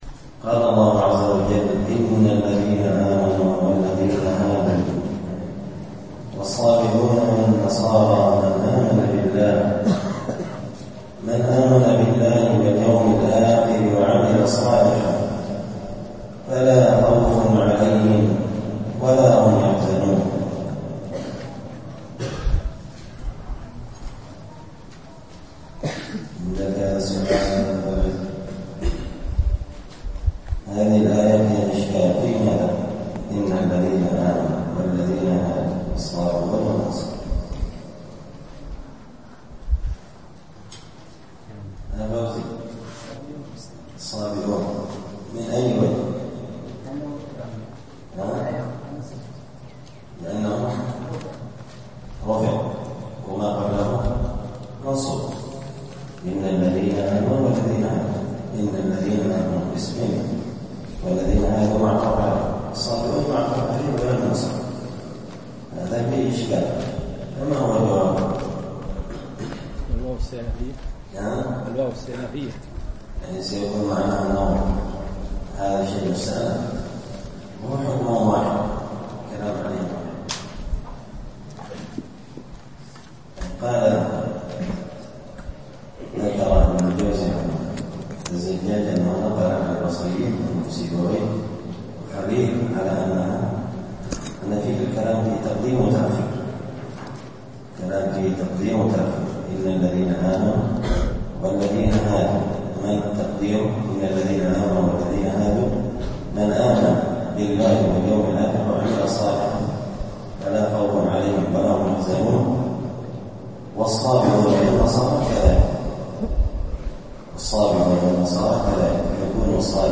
مسجد الفرقان